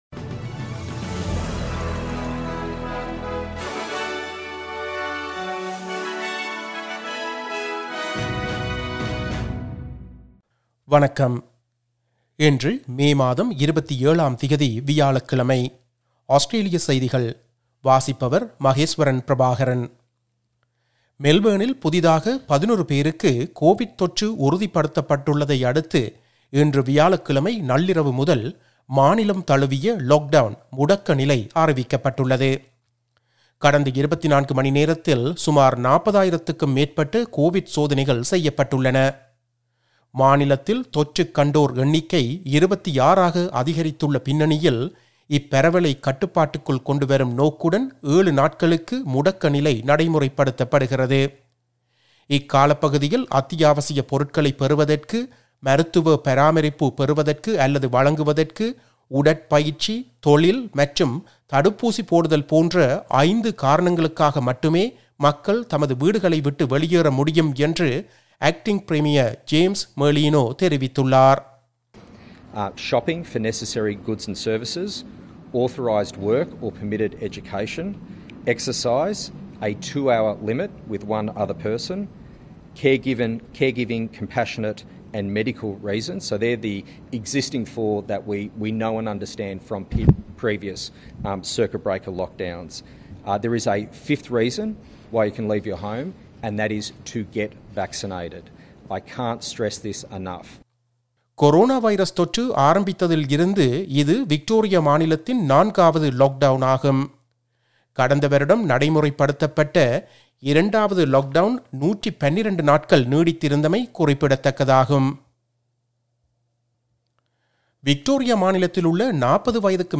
Australian news bulletin for Thursday 27 May 2021.